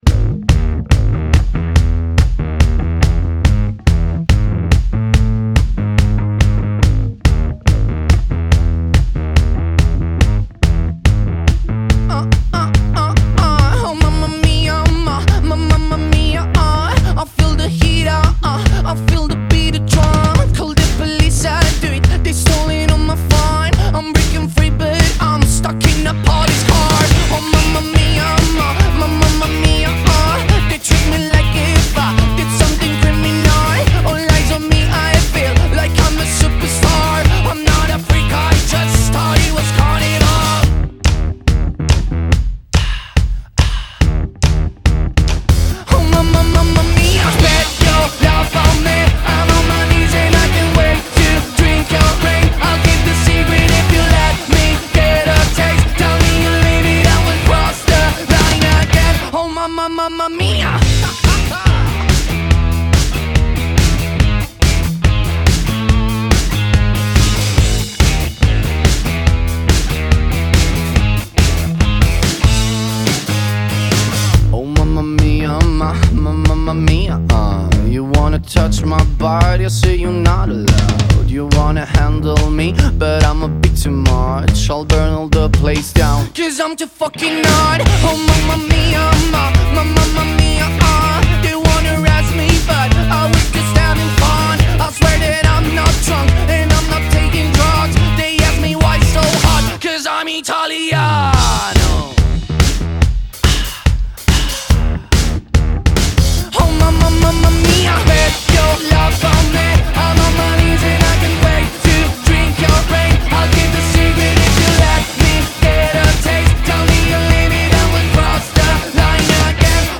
BPM142-143
Audio QualityPerfect (High Quality)
Rock song for StepMania, ITGmania, Project Outfox
Full Length Song (not arcade length cut)